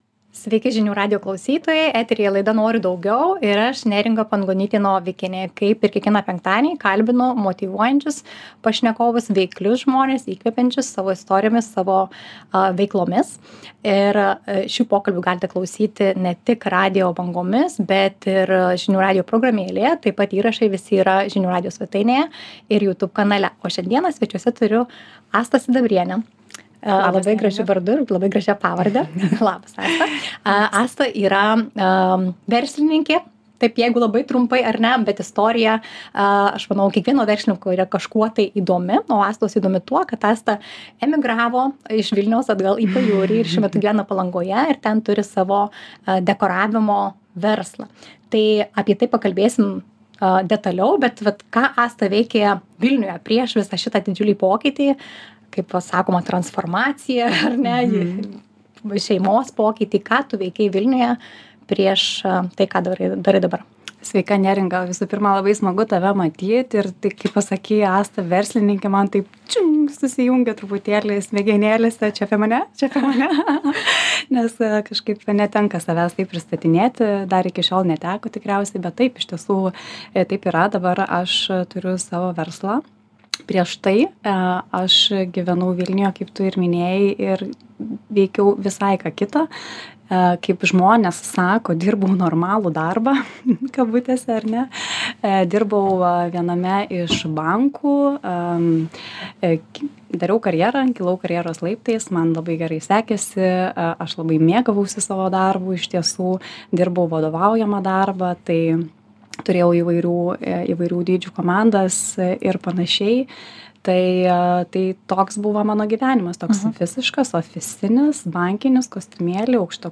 Pokalbyje išgirsite apie visus lydėjusius iššūkius ir kodėl šis sprendimas pasiteisino.